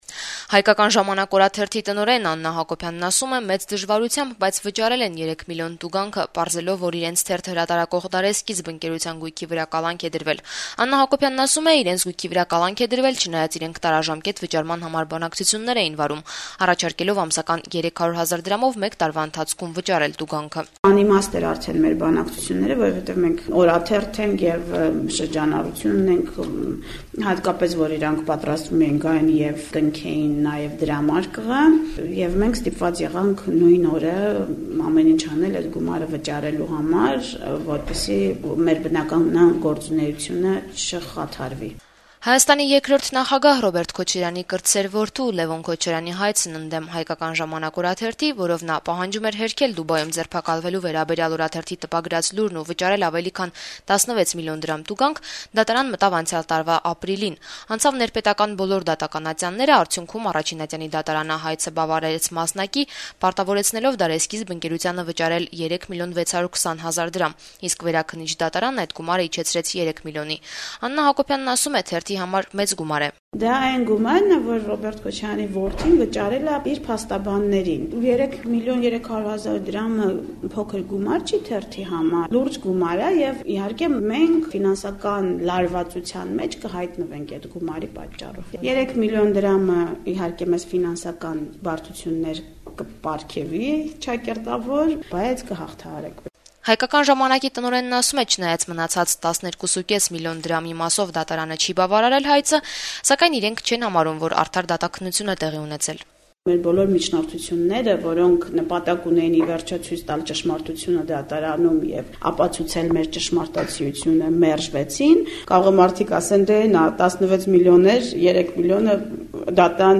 «Հայկական ժամանակ» օրաթերթի տնօրեն Աննա Հակոբյանը «Ազատություն» ռադիոկայանի հետ զրույցում այսօր փոխանցեց, որ թերթը «մեծ դժվարությամբ» վճարել է 3 միլիոն տուգանքը՝ պարզելով, որ իրենց թերթը հրատարակող «Դարեսկիզբ» ընկերության գույքի վրա կալանք է դրվել, եւ թերթը կարող է լույս տեսնել միայն գումարը վճարելուց հետո։